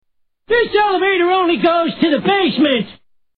Category: Television   Right: Personal